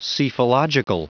Prononciation du mot psephological en anglais (fichier audio)
Prononciation du mot : psephological